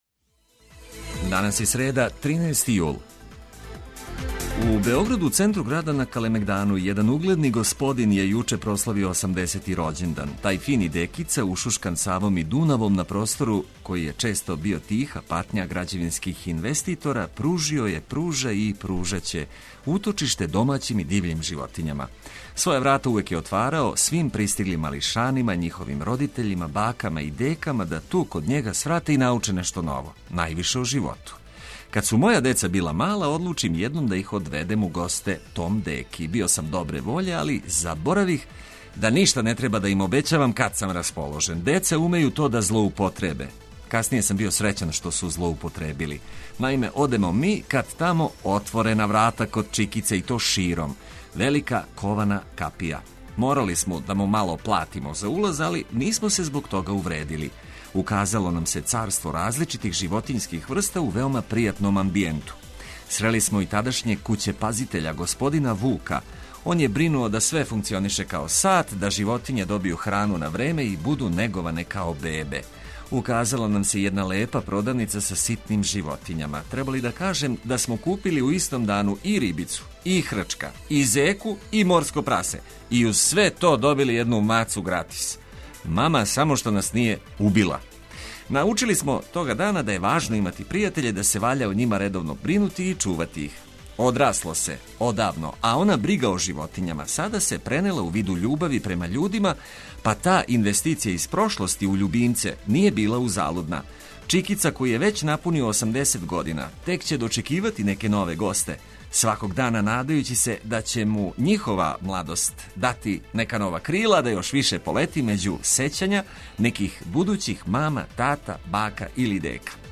Летње буђење, лако уз музику и корисне приче, у сусрет врућини која нам се спрема.